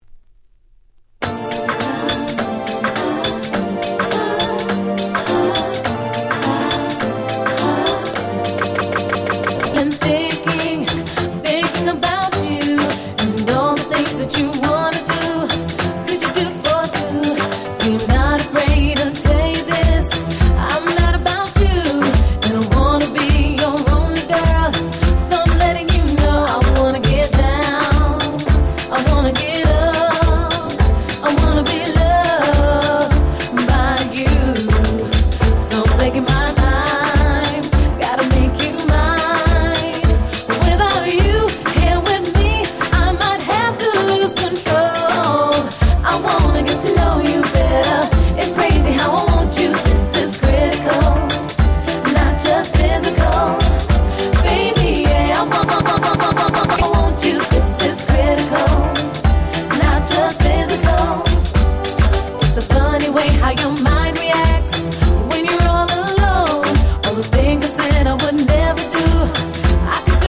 02' Super Hit Dancehall Riddim !!